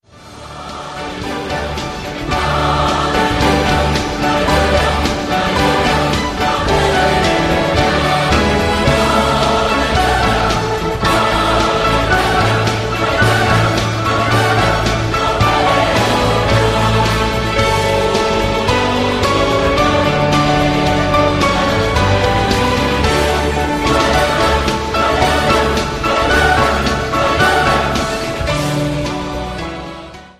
• Sachgebiet: Southern Gospel